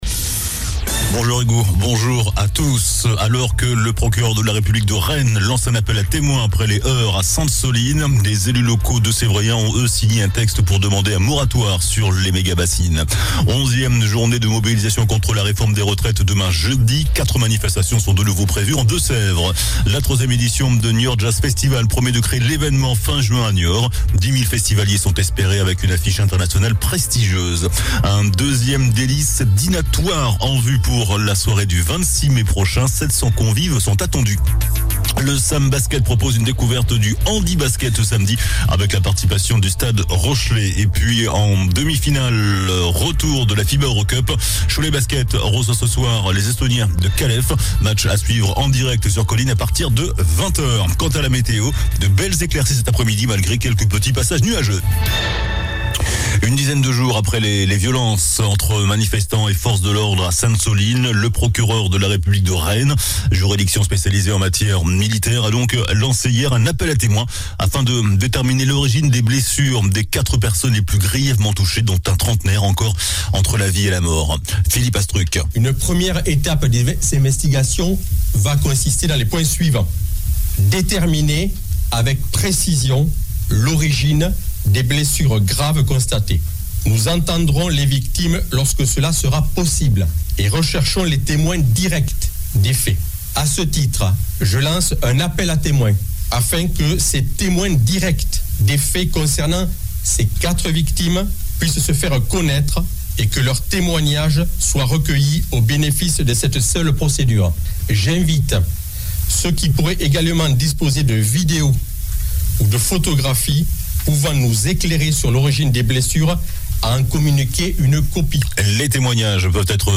JOURNAL DU MERCREDI 05 AVRIL ( MIDI )